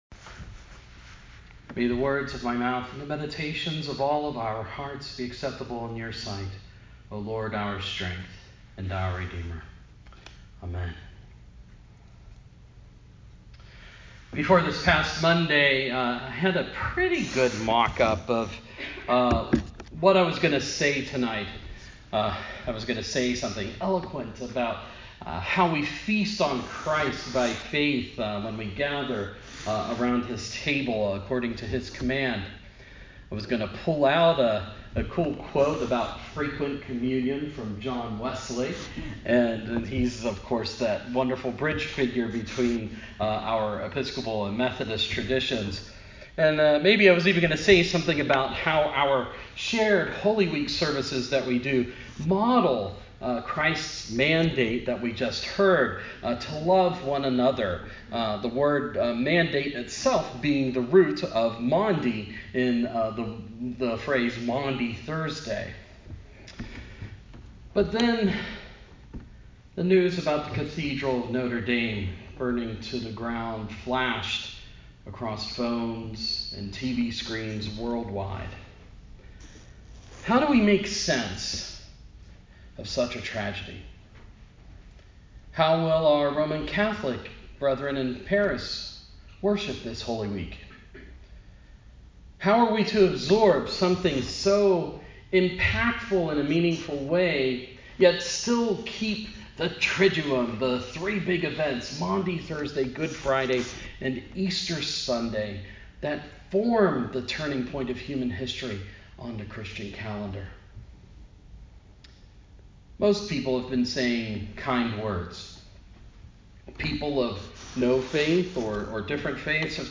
Sermon – Maundy Thursday